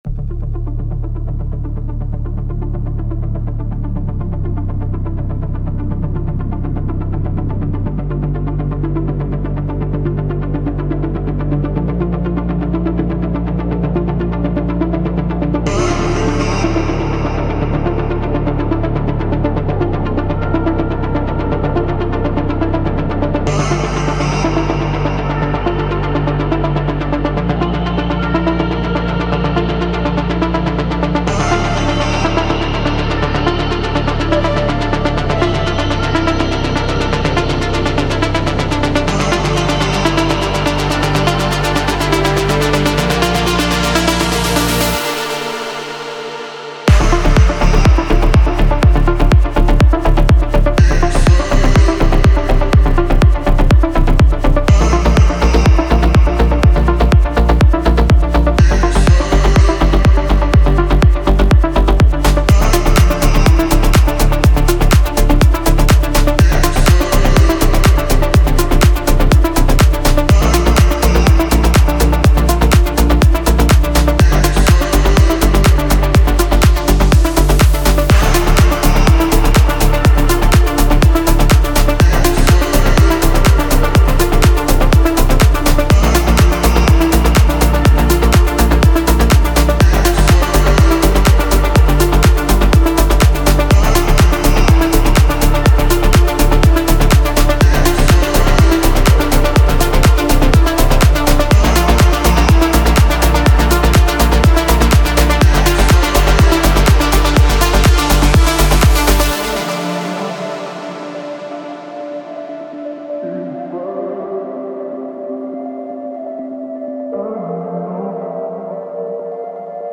• Жанр: House